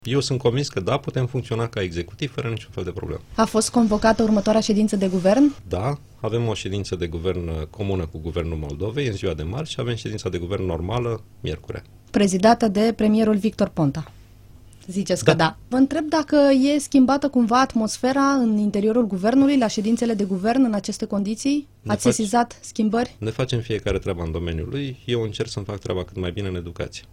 Este întrebarea la care a răspuns ministrul ministrul Educației, Sorin Cîmpeanu, invitat la Interviurile EuropaFM.